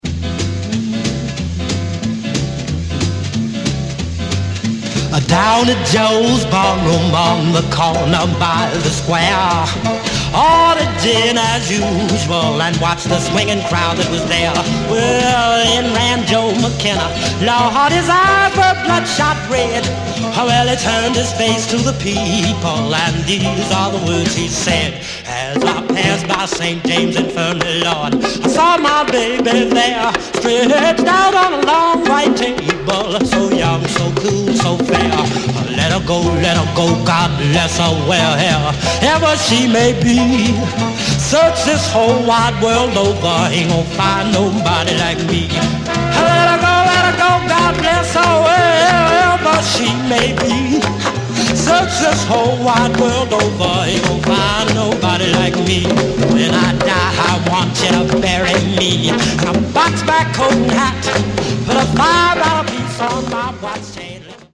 The backing music, mid tempo and he